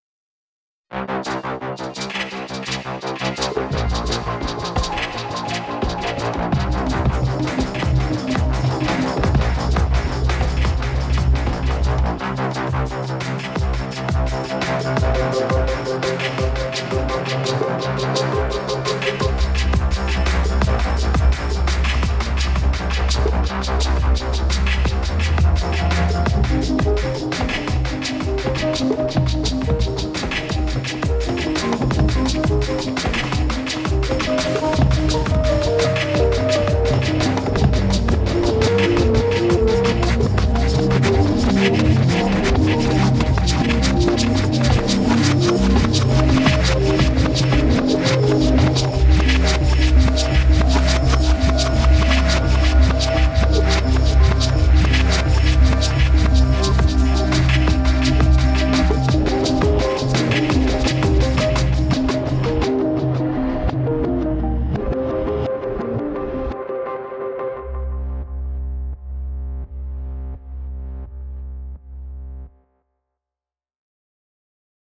Beim Beispiel 1 habe ich 2 verschiedene Loops verwendet.